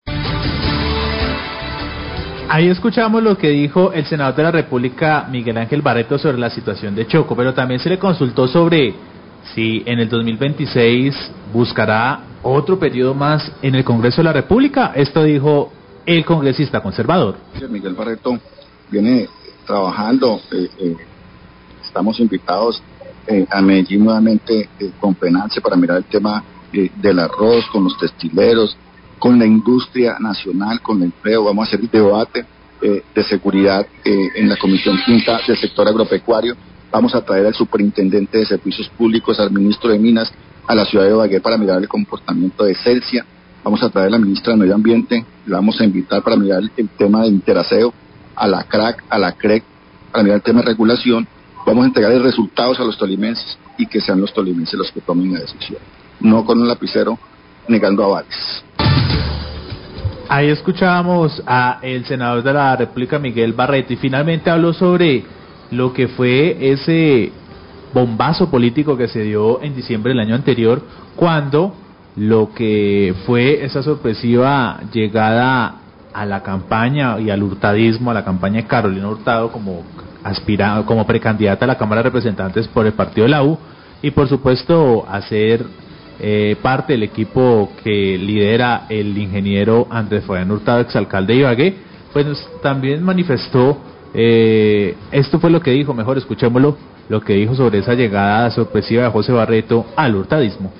Radio
Extensa entrevista con el senador Miguel Barreto donde habla sobre temas políticos y de sus funciones.  Agrega que que llevará al Superintendente de Servicios Públicos y al Ministro de Minas a la ciudad de Ibagué para evaluar el comportamiento de Celsia, Alcanos e Interaseo.